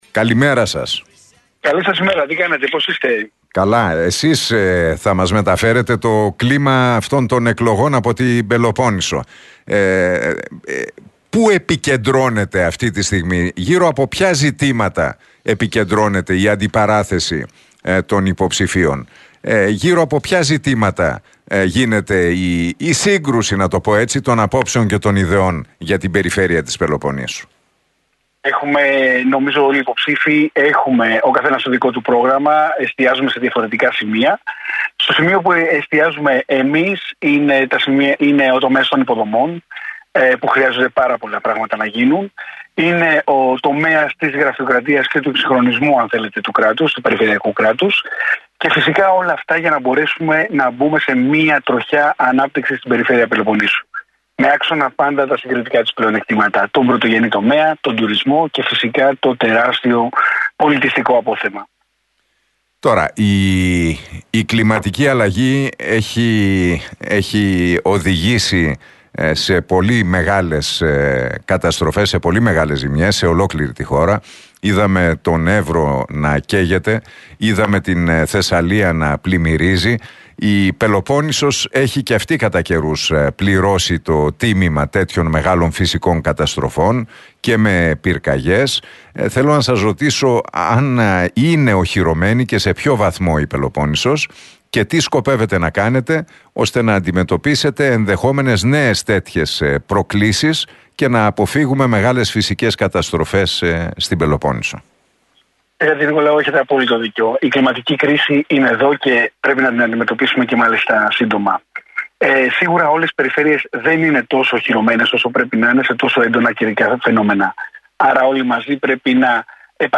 Ο υποψήφιος Περιφερειάρχης Πελοποννήσου, Δημήτρης Πτωχός, μίλησε στον Realfm 97,8 και στην εκπομπή του Νίκου Χατζηνικολάου.